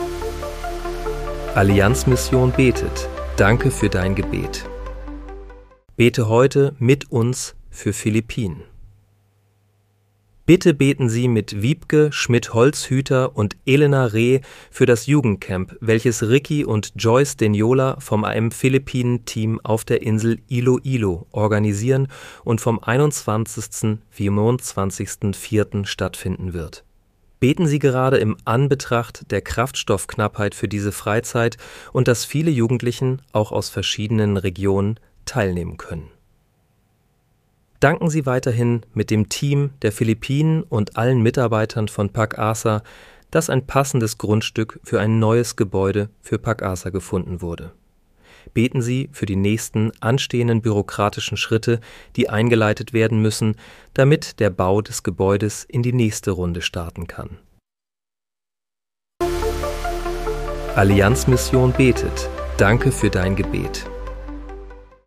Bete am 22. April 2026 mit uns für Philippinen. (KI-generiert mit